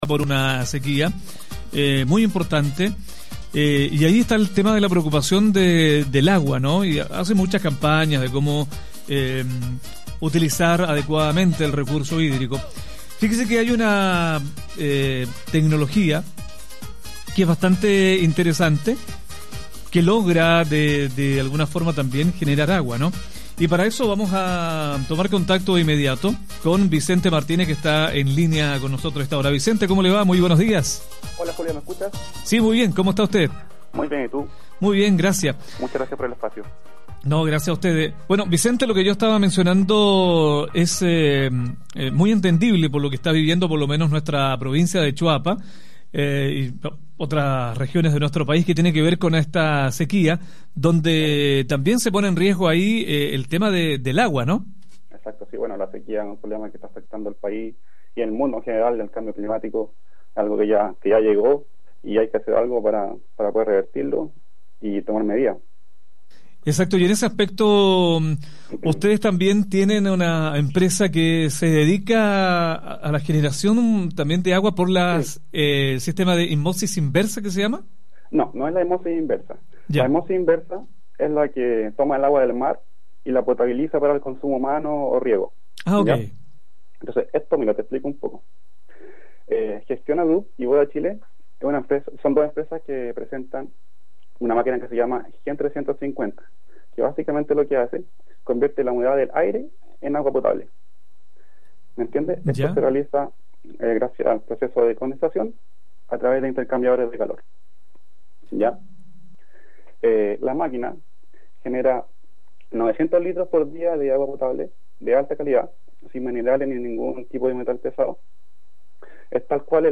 RADIO_MADRIGAL-entrevista.mp3